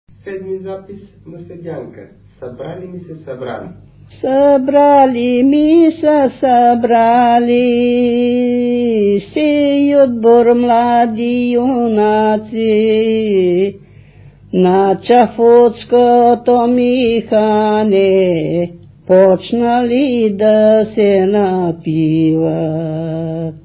музикална класификация Песен
форма Четириредична
размер Седем осми
фактура Едногласна
начин на изпълнение Солово изпълнение на песен
битова функция На седянка
фолклорна област Средна Северна България
начин на записване Магнетофонна лента